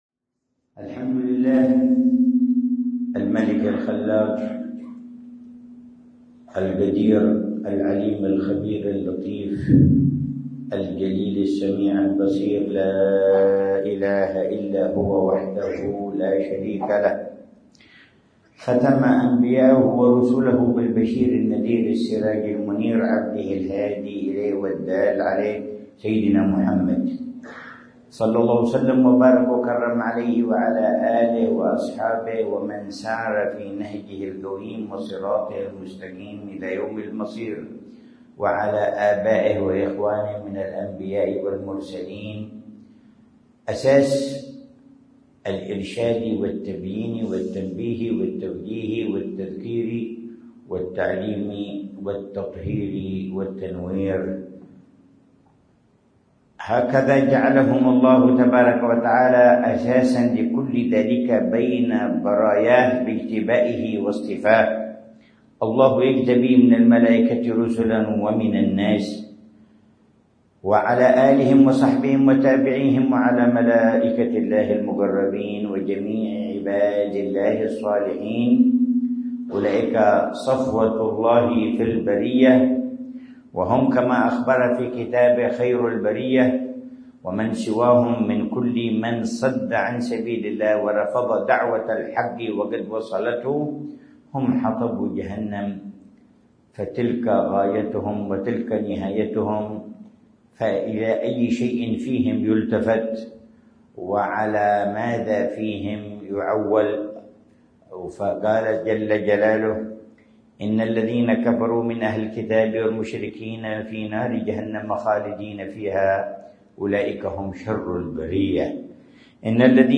محاضرة في افتتاح الحلقة العلمية الخامسة في ذكرى الحبيب علي المشهور بن حفيظ 1446هـ
محاضرة العلامة الحبيب عمر بن محمد بن حفيظ في افتتاح الحلقة العلمية الخامسة في ذكرى وفاة العلامة الحبيب علي المشهور بن محمد بن حفيظ في قاعة الحبيب عبد القادر بن أحمد السقاف، في منطقة الحسيسة بوادي ح